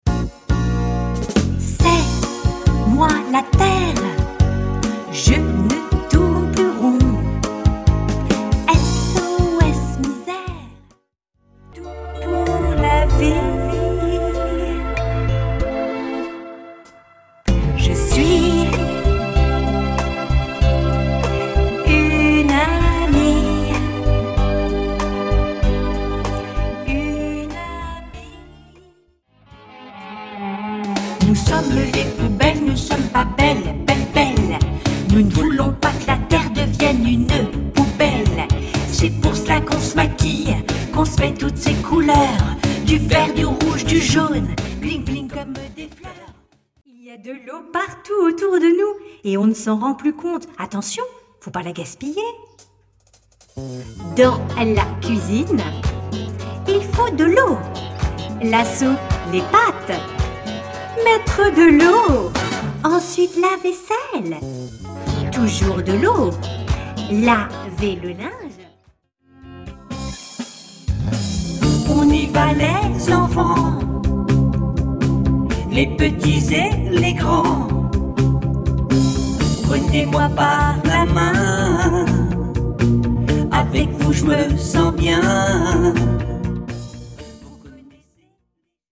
EXTRAITS DE LA BANDE SON